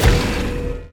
teleport_arrival.ogg